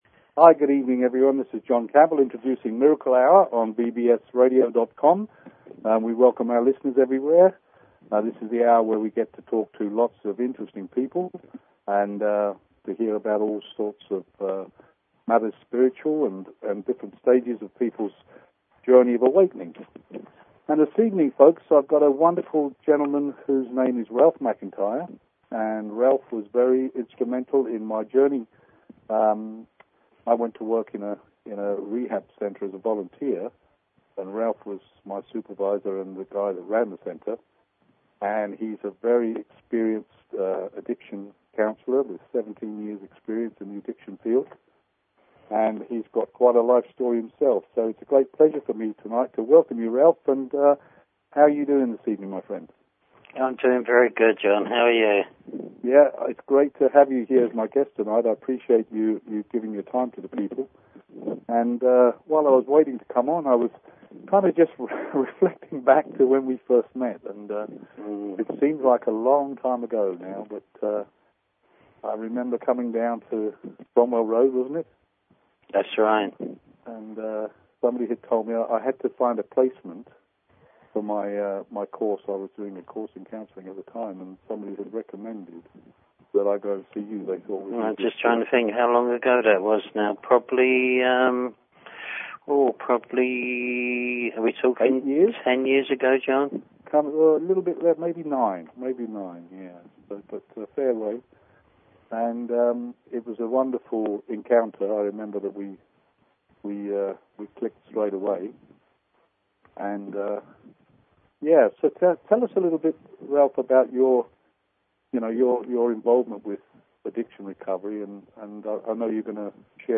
Talk Show Episode, Audio Podcast, Miracle_Hour and Courtesy of BBS Radio on , show guests , about , categorized as